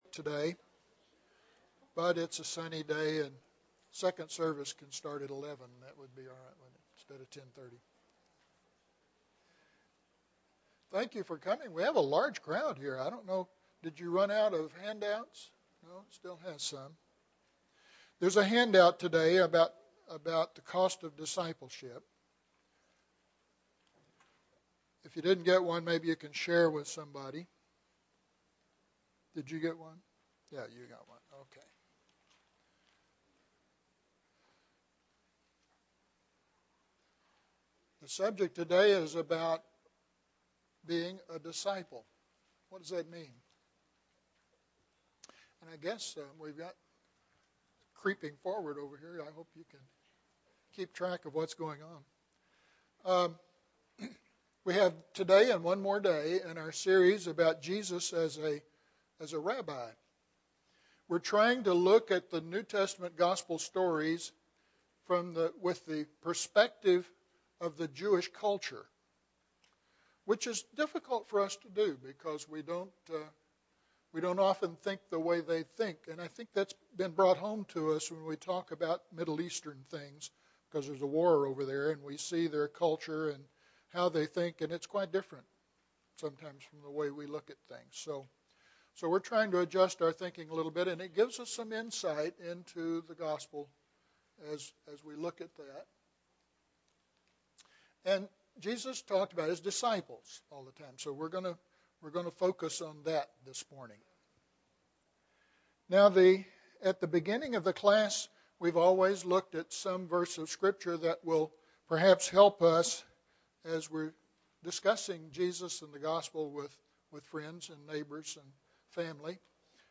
The Meaning of Discipleship; Knowing About Jesus and Being Like Jesus (12 of 13) – Bible Lesson Recording
Sunday AM Bible Class